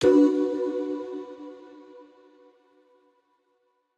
Doo Vox Chord.wav